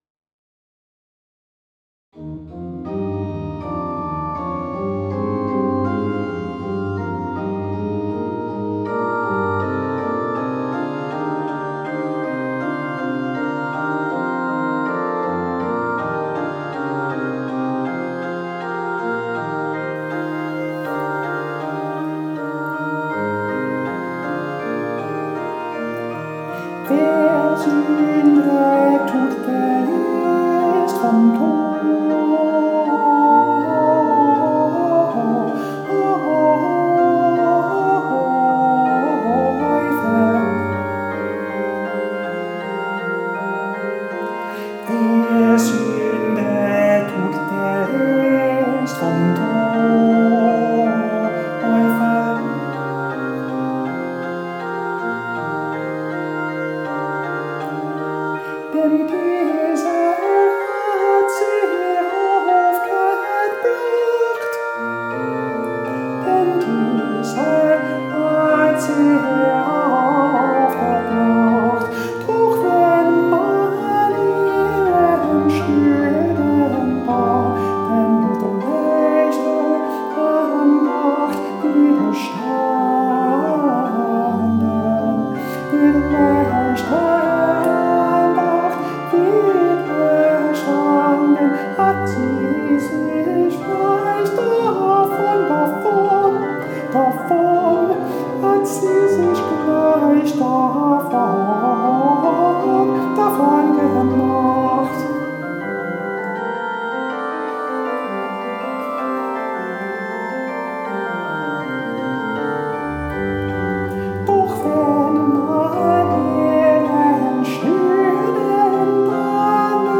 Compared to the original, it loses some rambunctious energy and complexity, but it gains some lyricism and contrapuntal clarity.
recording of the reduced version, with me singing and the computer playing the keyboard part on the virtual organ.